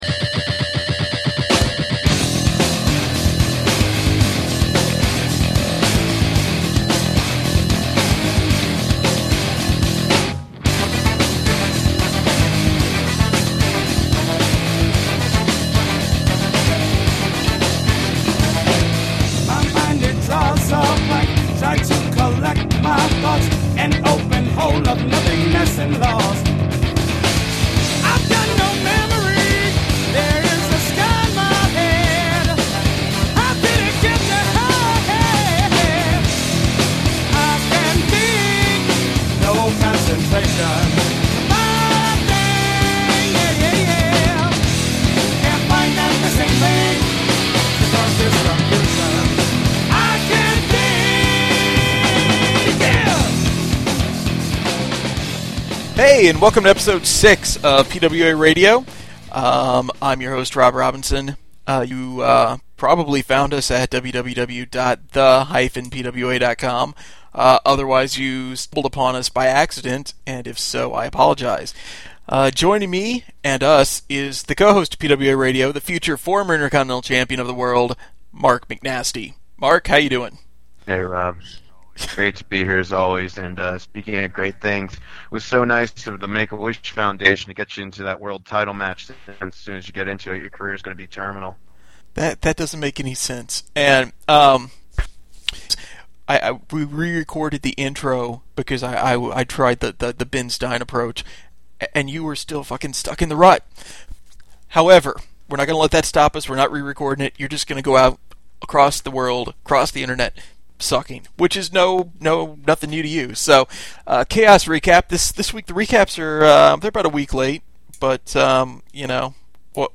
Hopefully got the kinks worked out of Skype for next week, but only time will tell.